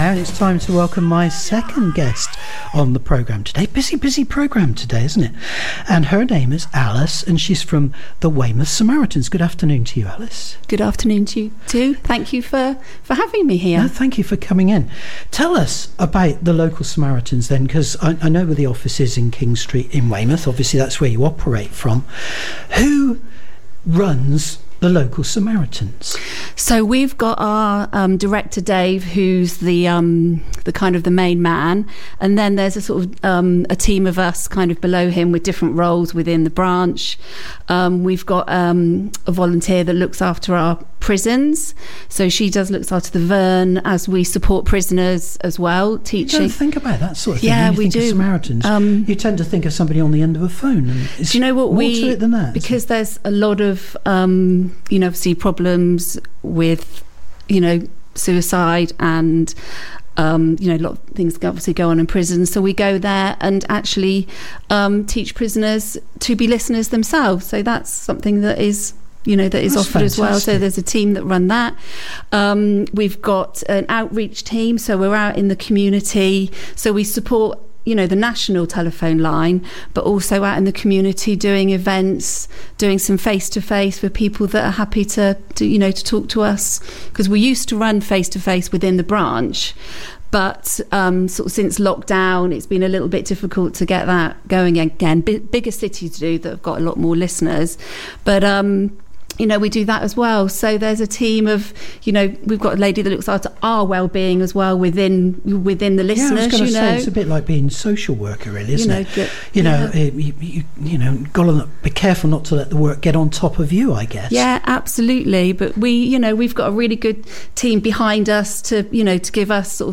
A recent visitor to the studio